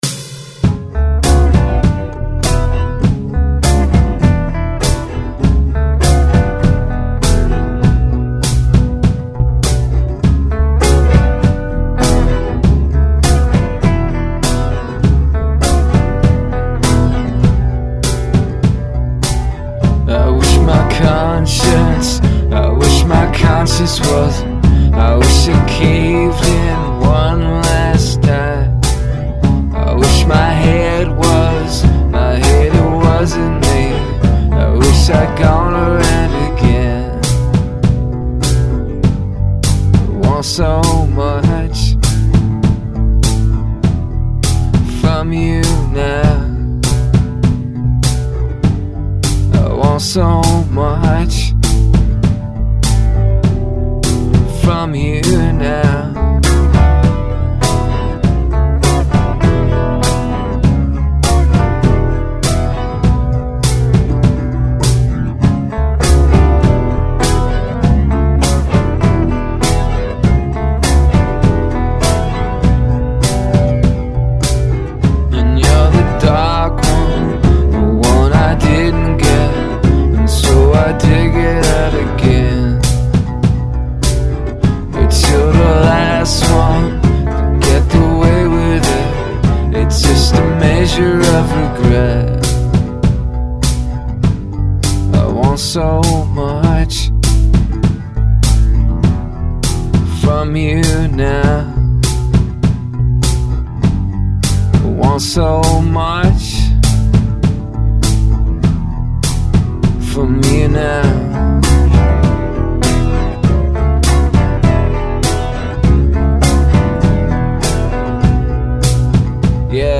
All I know is that this recording has a guest vocalist